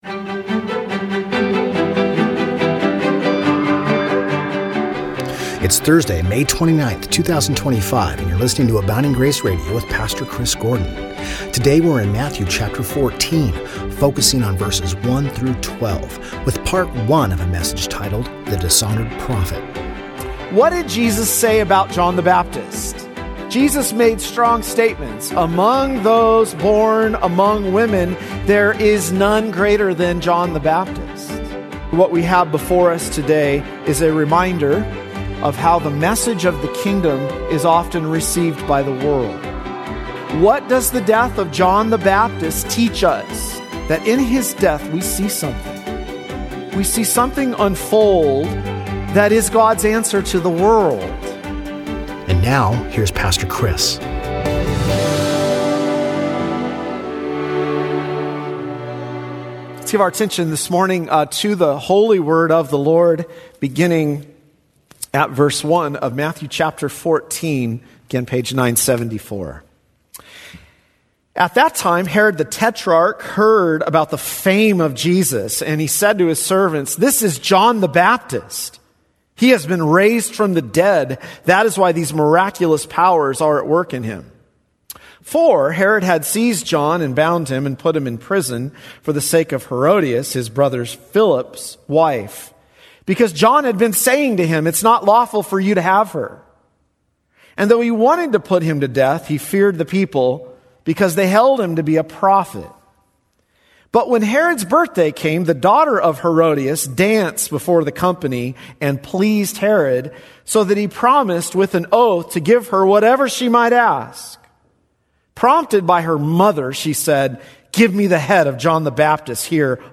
Original Sermon